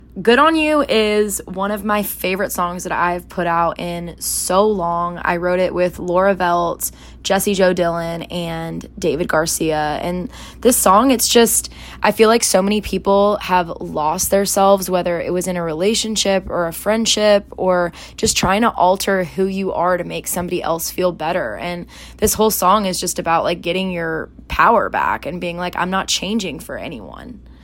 Priscilla Block talks about her new song, "Good On You."